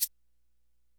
SHAKERIK.WAV